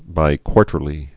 (bī-kwôrtər-lē)